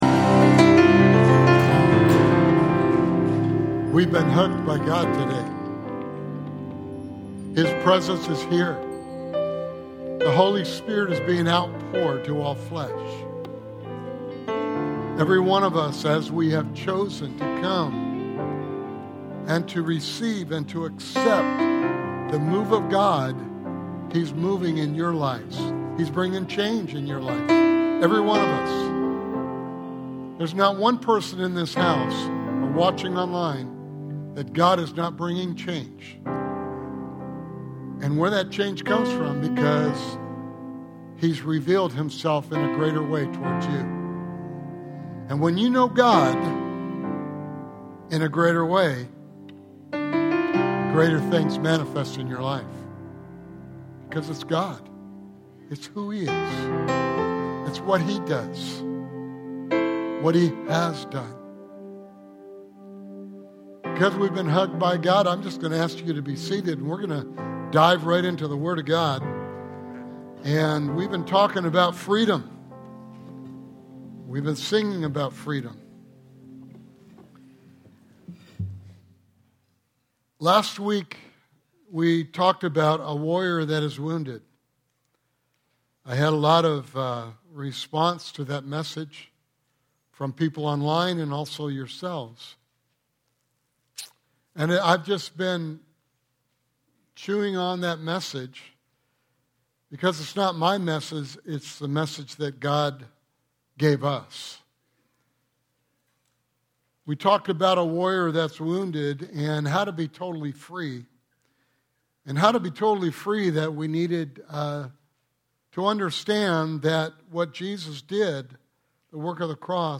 Sermon Series: I Am Free